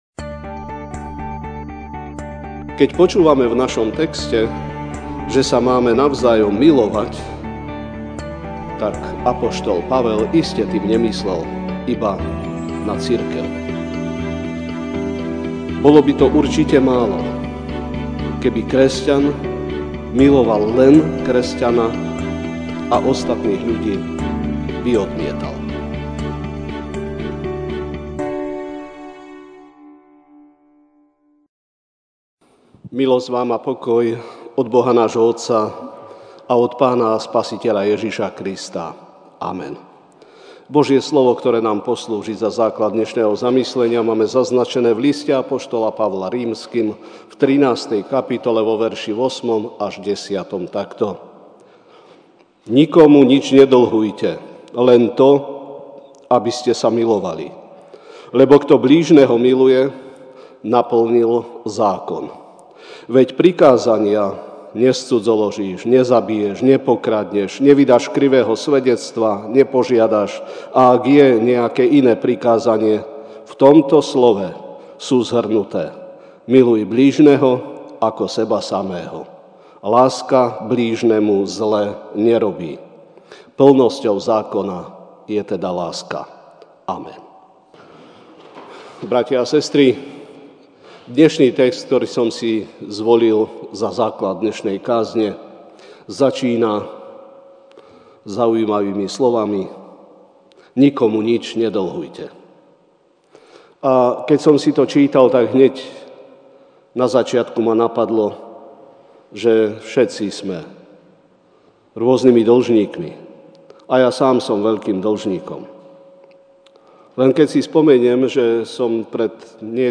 feb 03, 2019 Láska k druhým ľuďom MP3 SUBSCRIBE on iTunes(Podcast) Notes Sermons in this Series Večerná kázeň: Láska k druhým ľuďom (R 13, 8-10) Nikomu nič nedlhujte, len to, aby ste sa milovali. Lebo kto blížneho miluje, naplnil zákon.